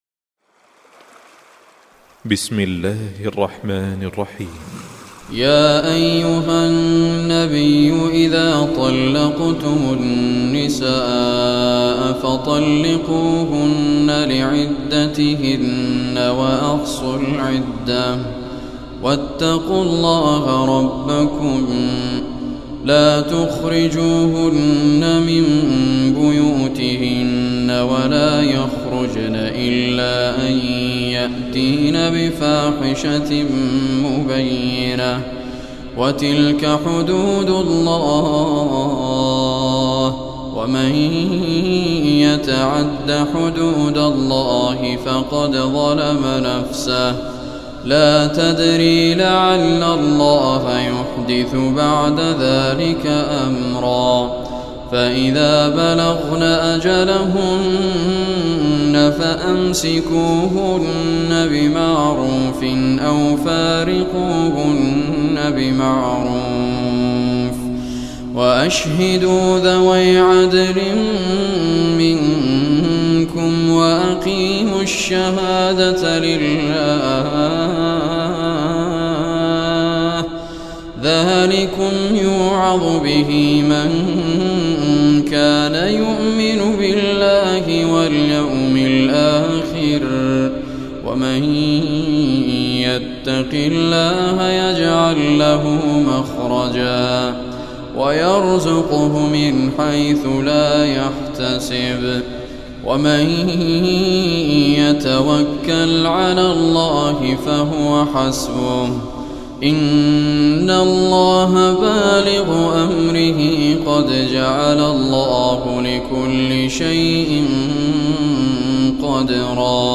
Surah Talaq Recitation
quran recitation